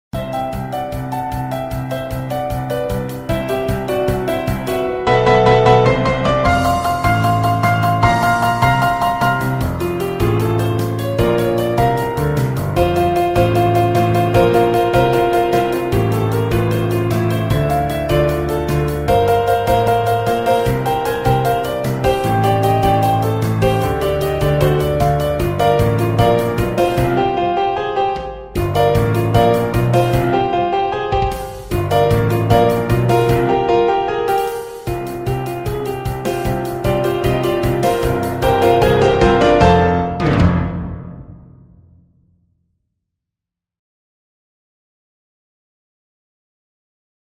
piano + batería MIDI y poco más